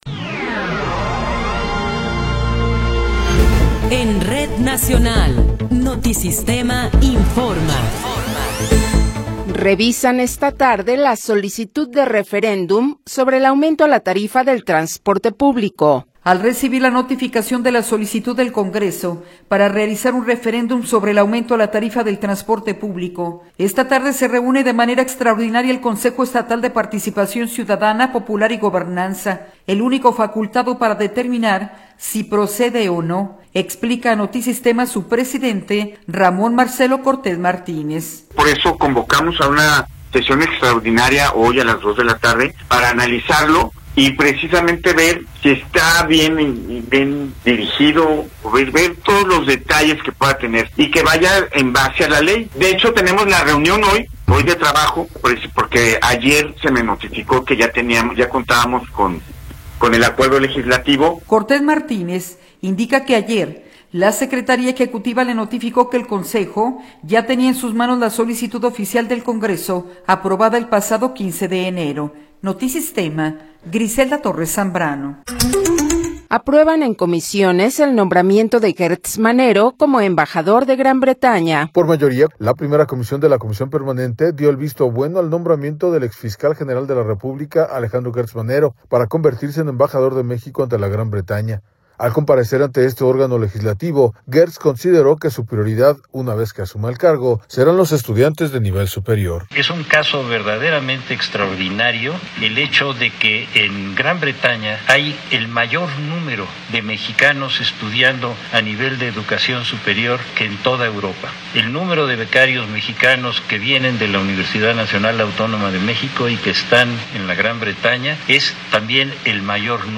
Noticiero 15 hrs. – 22 de Enero de 2026